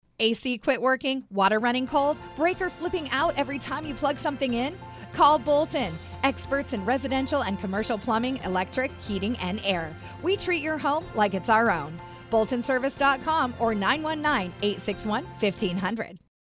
As Heard on Capitol Broadcasting